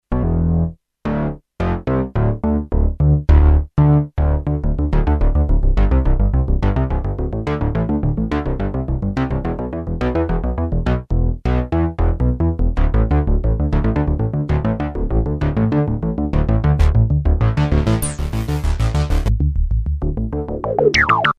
adsr FILTER lowpass resonant analog VCF based on 8X CEM 3379 or 3389 digitally controlled quantised in 99 digital scaled values.
cem 3379 filters demo HEAR filter in action
Class: Synthesizer